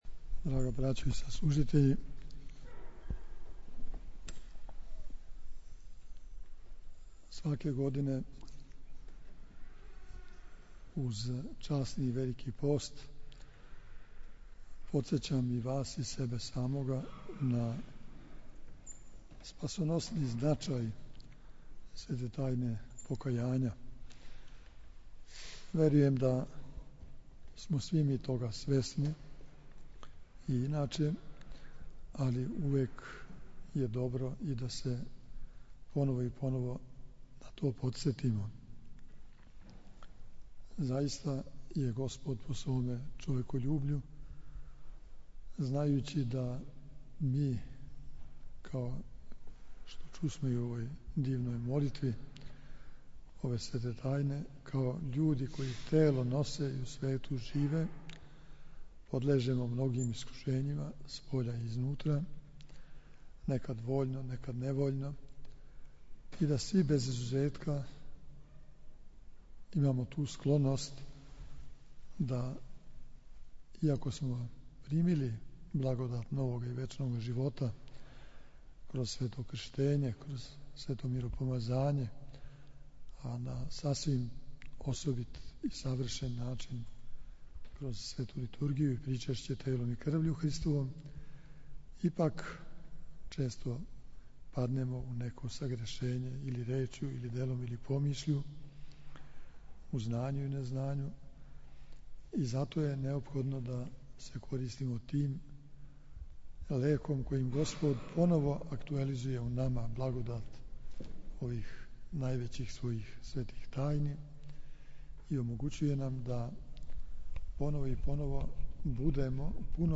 У понедељак треће седмице Часнога поста, 12. марта 2012. године, после јутарњег богослужења у Саборном храму у Новом Саду, предвођени својим архијерејем Његовим Преосвештенством Епископом бачким Господином др Иринејем, новосадски свештеници приступили су светој Тајни исповести.
Своју браћу и саслужитеље владика Иринеј је поучио беседом о спасоносном значају свете Тајне покајања.